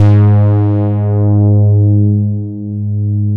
MODULAR G#3F.wav